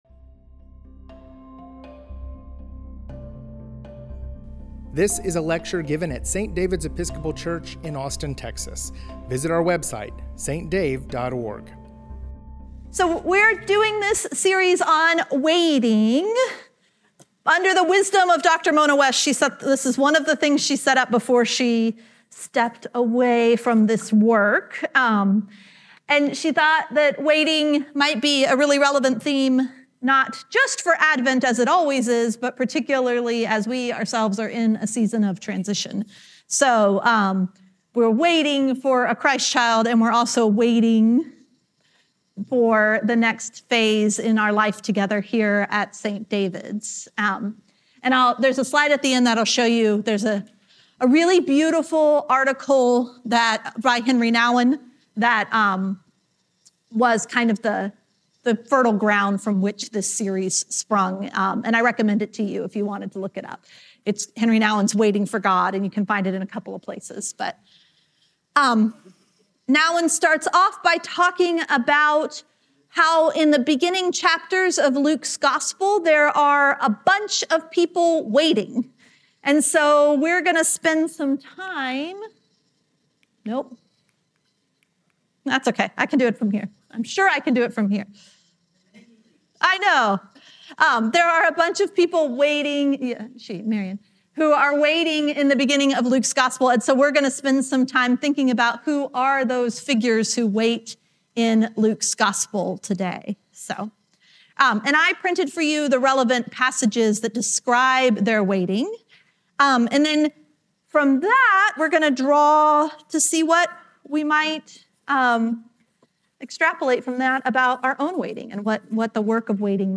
This is the first lecture in our Advent Waiting series.